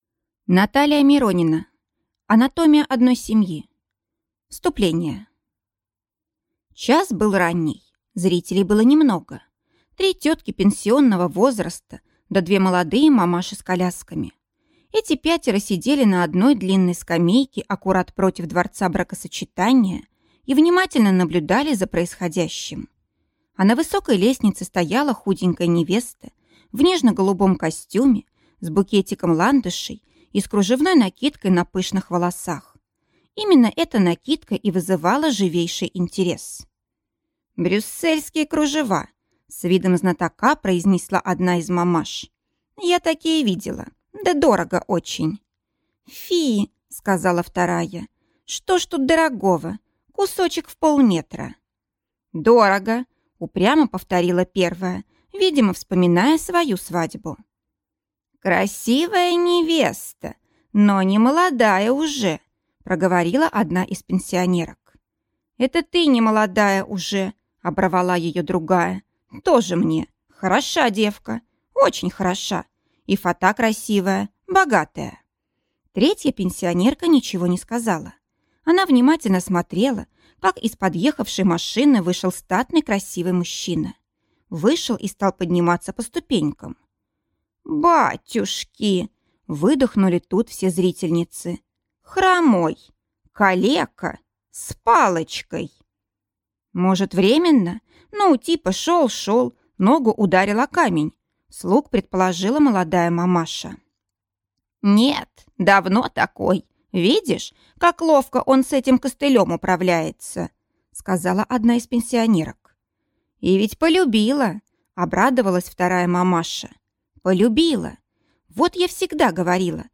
Аудиокнига Анатомия одной семьи | Библиотека аудиокниг
Прослушать и бесплатно скачать фрагмент аудиокниги